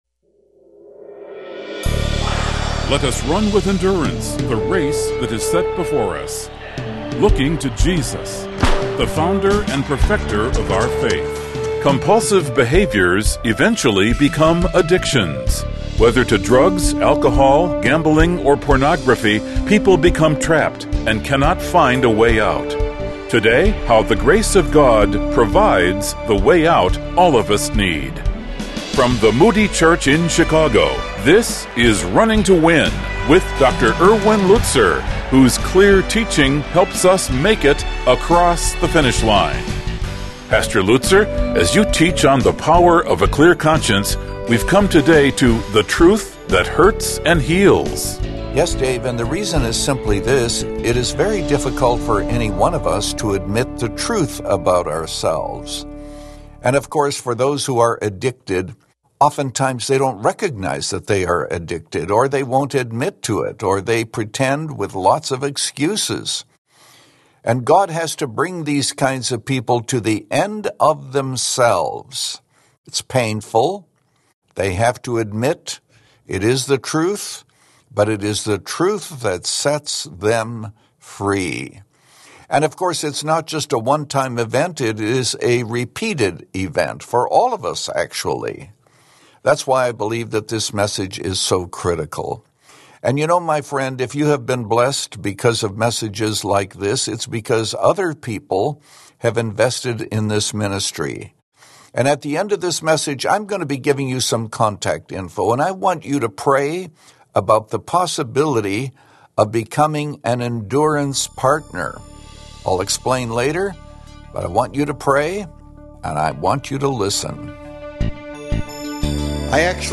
In this message from John 8